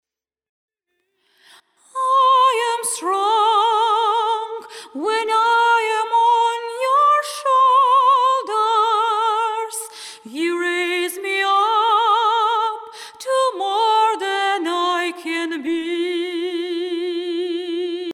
Tone adjustment (elimination of intonation errors – correction of false sounds)
Time correction (alignment of syllables with the rhythm)
Breathing correction (removal of superfluous breaths)
Vocal Tuned
tuned_vocal.mp3